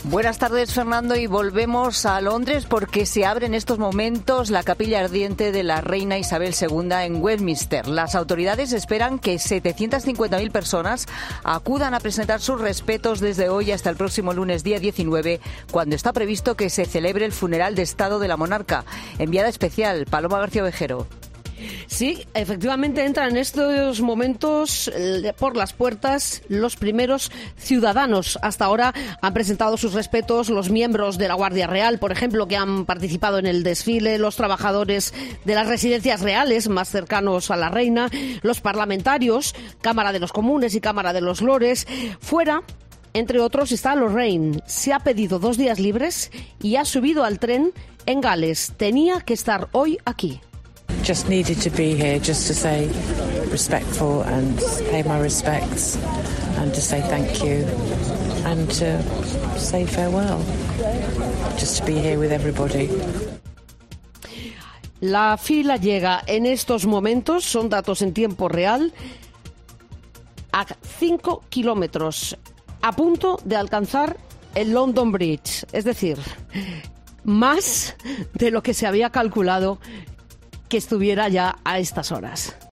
Así te ha contado COPE desde Londres la apertura de la capilla ardiente por Isabel II